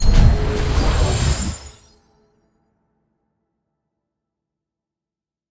wall-open.ogg